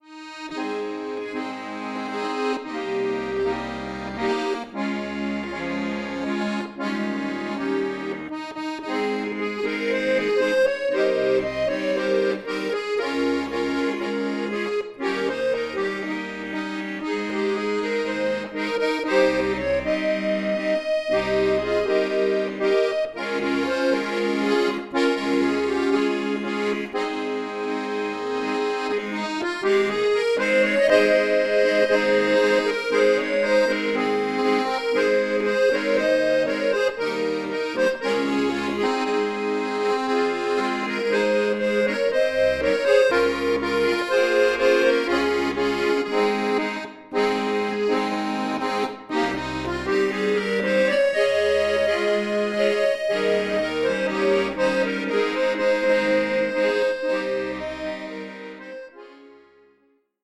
Traditional
Christmas Carol